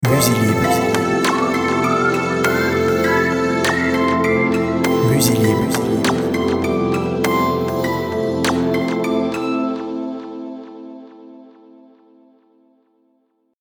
ambient, zen, soft, aquatic, mysterious